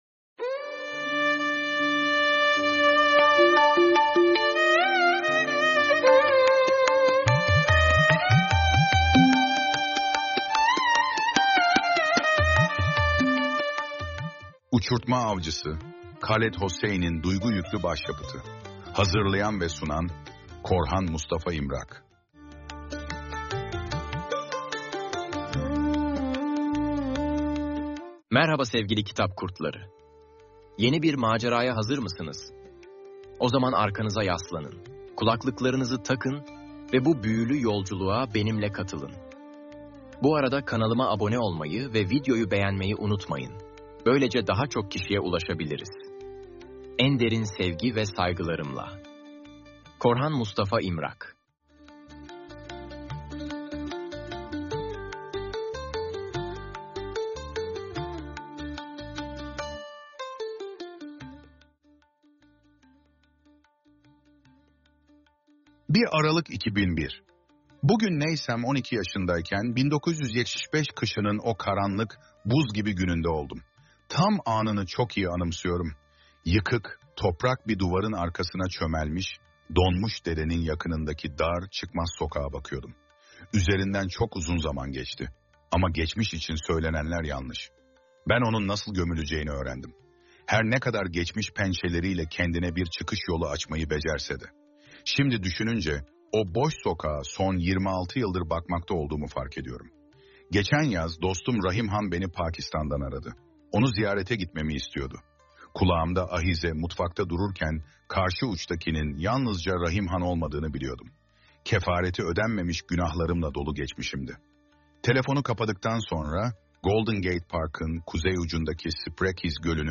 Uçurtma Avcısı – Khaled Hosse Sesli Kitap Dinle Part 1